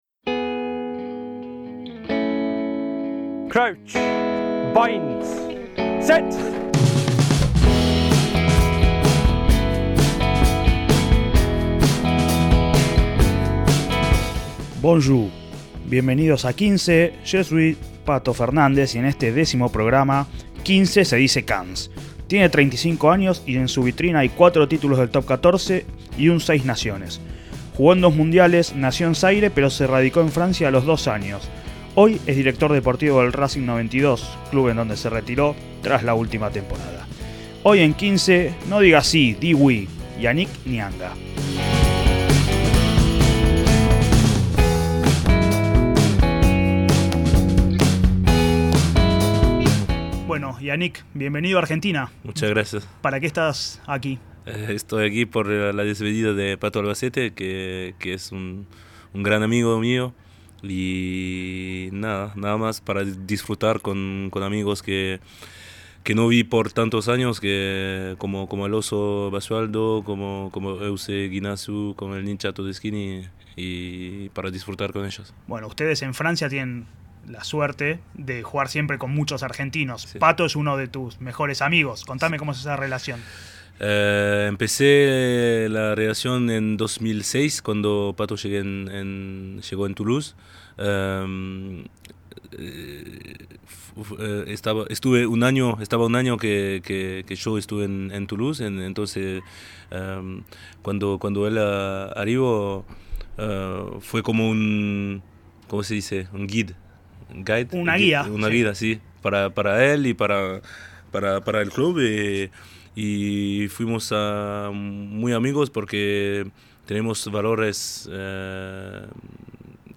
XV celebra su décimo programa con un gran protagonista del rugby internacional: Disfrutamos un distendido mano a mano en perfecto español con el ex tercera línea francés y actual Director Deportivo del Racing 92, Yannick Nyanga. Fue cuatro veces campeón del Top 14, una vez del Seis Naciones y dos Mundiales disputados, jugó con muchísimos argentinos y ya es uno más, no te podés perder esta entrevista!